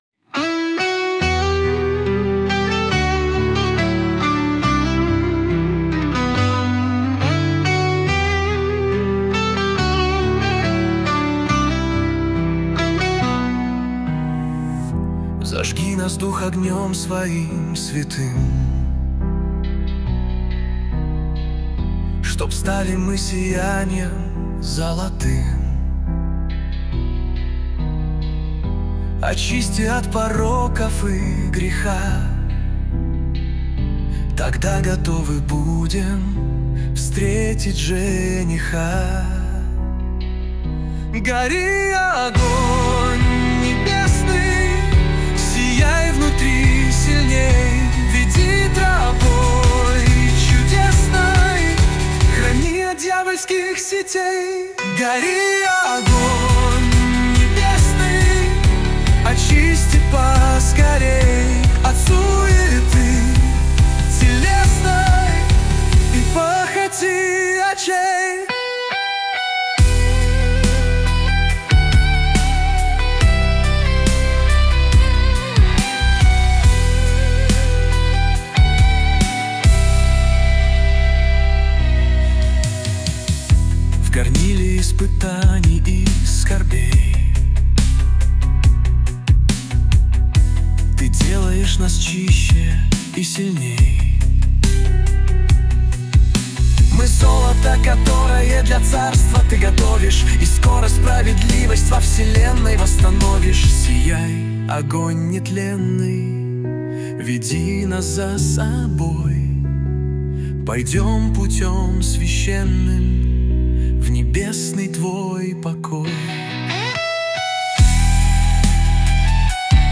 B/Em/D/C/G/Em/ B/Em/D/C/Bsus4/B/Em
584 просмотра 1580 прослушиваний 149 скачиваний BPM: 70